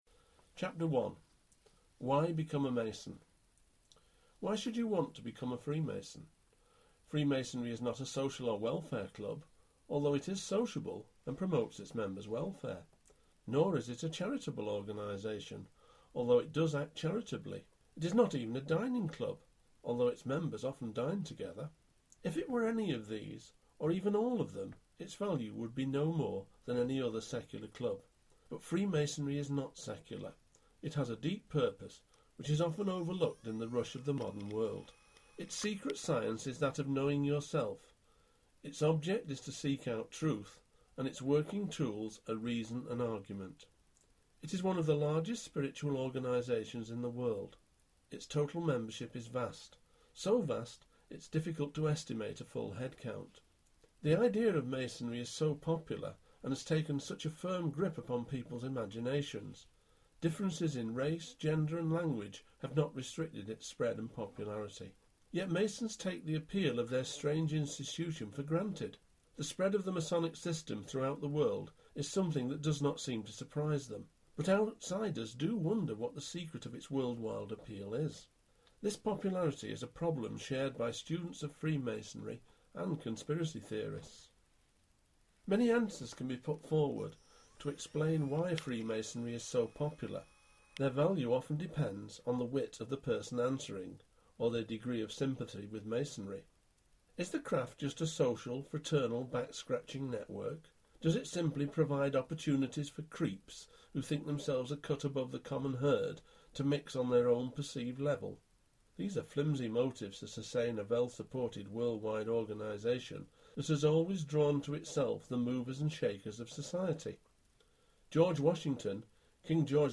reading from The Secret Science of Masonic Initiation